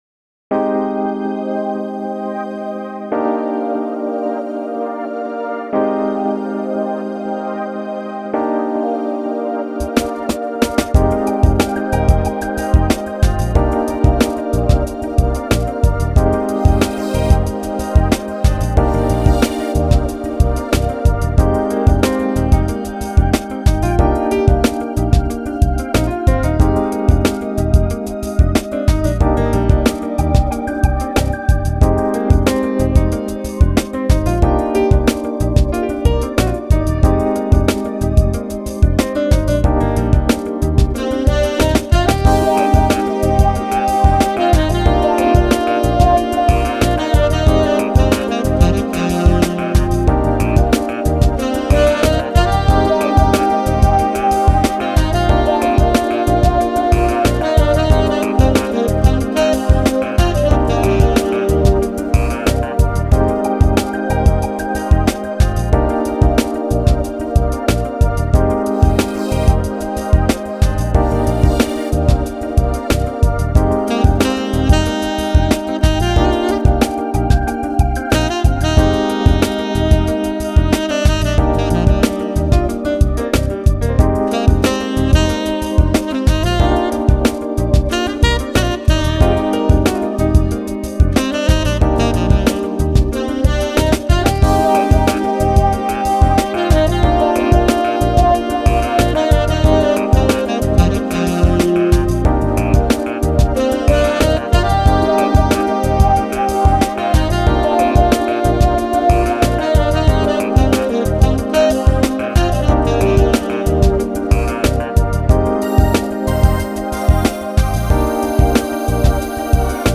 Genre: Smooth Jazz, Chillout, Longe